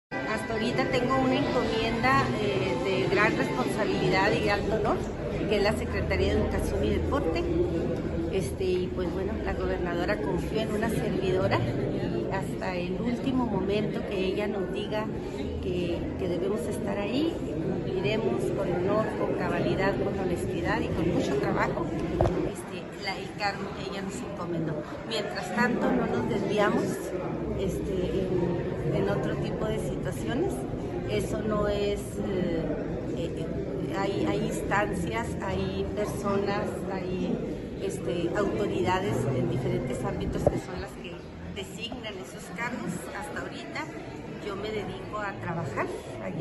AUDIO: SANDRA ELENA GUTÍERREZ FIERRO, TITULAR DE LA SECRETARÍA DE EDUCACIÓN Y DEPORTE (SEyD)